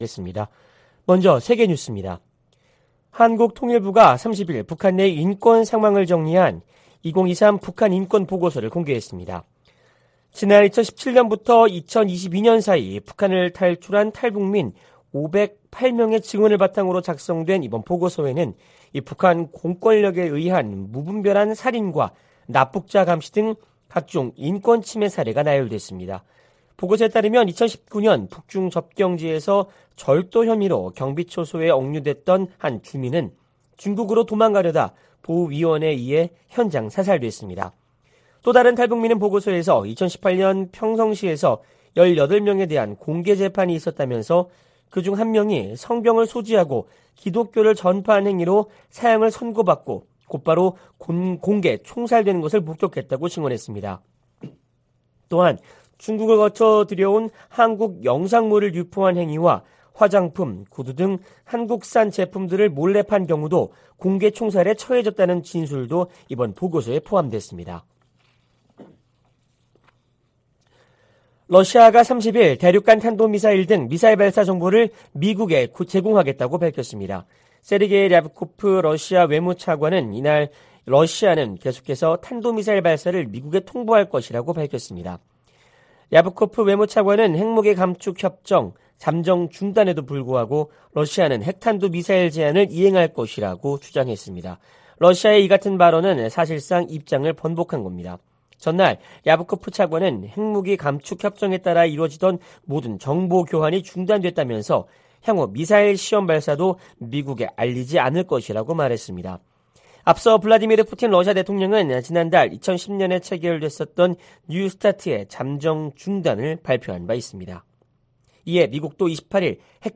VOA 한국어 '출발 뉴스 쇼', 2023년 3월 31일 방송입니다. 조 바이든 미국 대통령과 윤석열 한국 대통령은 제2차 민주주의 정상회의에서 공동성명을 내고 양국은 공동의 민주적 가치와 인권 존중을 기반으로 깊은 유대를 공유하고 있다고 밝혔습니다. 미 국무부는 한반도 비핵화 의지를 거듭 확인했습니다. 미국 공화당 중진 상원의원이 한국과의 핵 연습을 확대해야 한다고 주장했습니다.